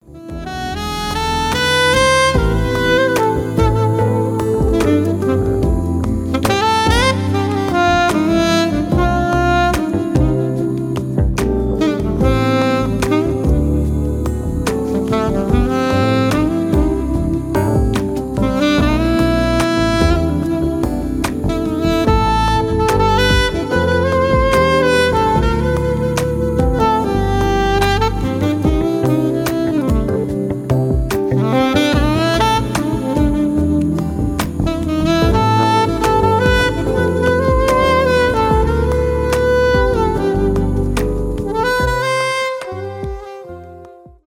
поп
инструментальные
романтические , саксофон
без слов